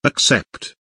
accept kelimesinin anlamı, resimli anlatımı ve sesli okunuşu